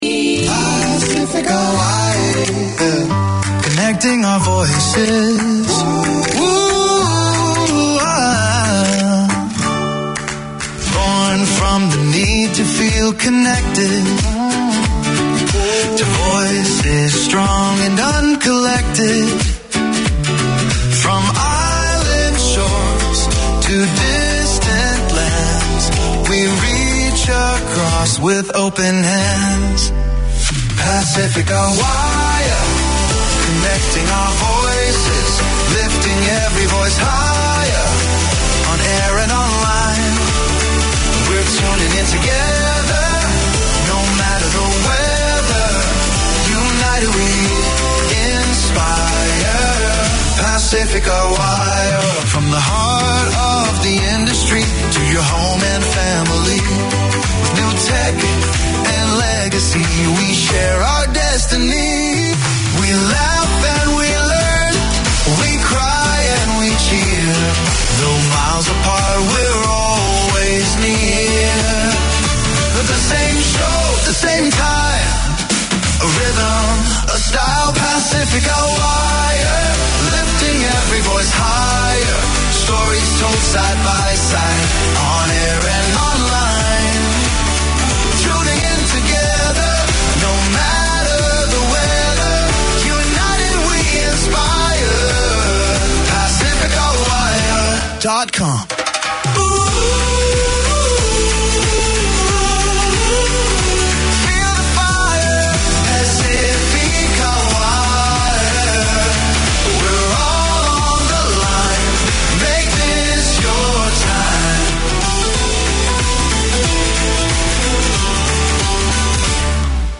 A combination of modern and organic motifs, Art Deco design continues to be captivating and collectable. Deco is the passion of the presenters of this programme that explores the local and global Deco scene, preservation and heritage, the buildings, jewellery and furnishings with interviews, music, notice of coming events; a sharing of the knowledge of Club Moderne, the Art Deco Society of Auckland.